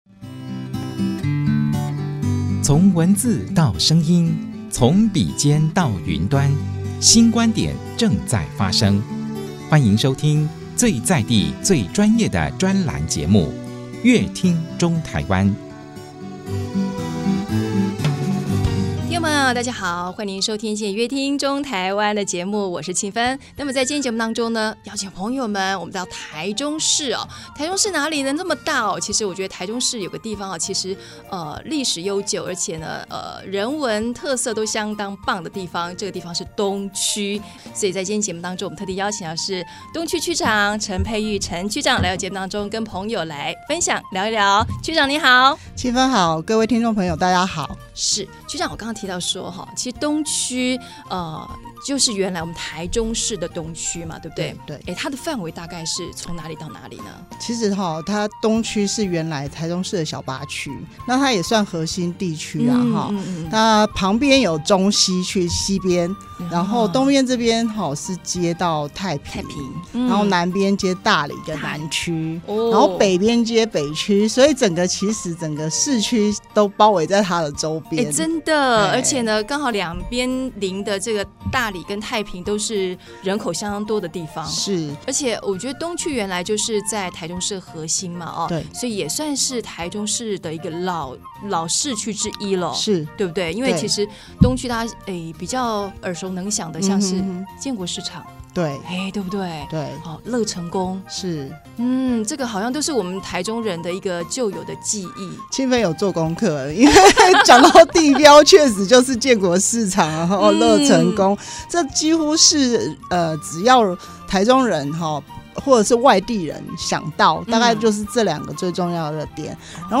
本集來賓：臺中市東區區公所陳佩玉區長 本集主題：「觀光翻轉 打造東區新亮點 」 本集內容： 位於台中市核心，發